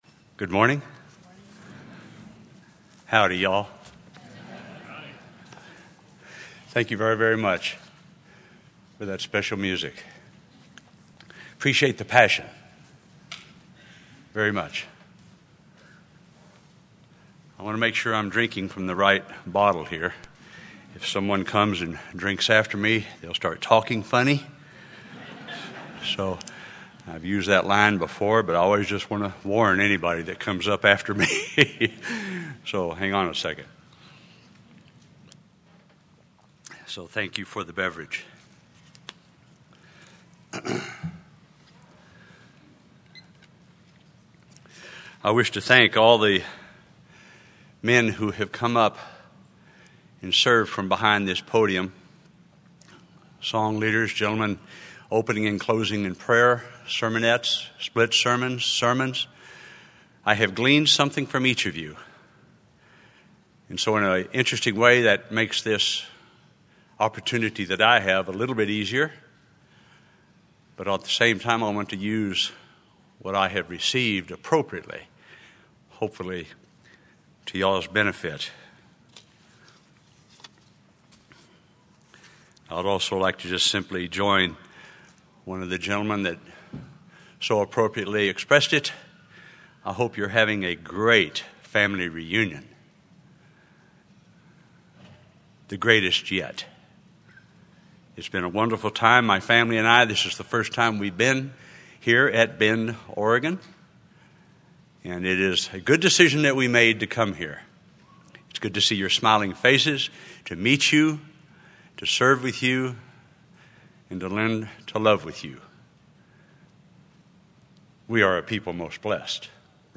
This sermon was given at the Bend-Redmond, Oregon 2018 Feast site.